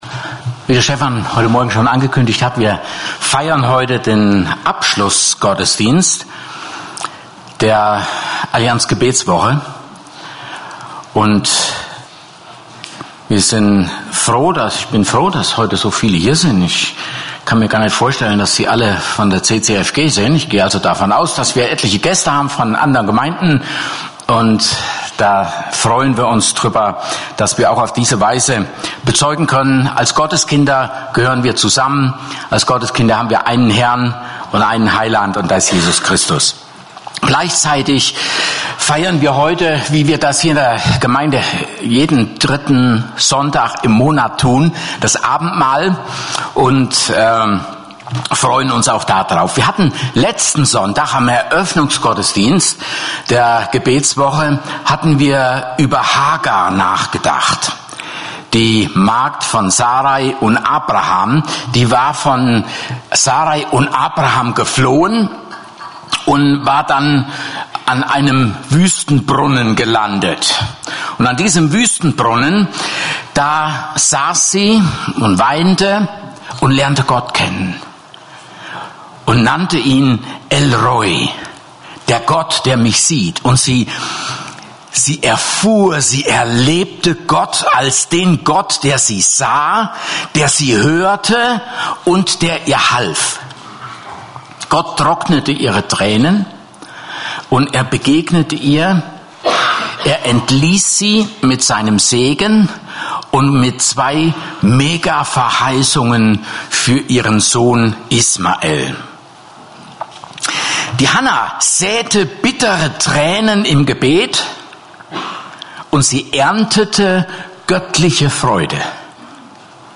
Psalm 126 – Allianzabschlussgottesdienst